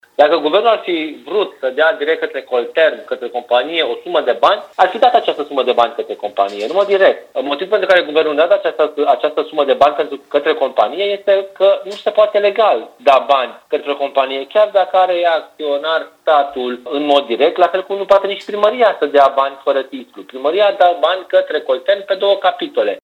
Viceprimarul Ruben Lațcău afirmă că Primăria nu poate să plătească acei bani Coltermului, pentru că nu i se cuvin.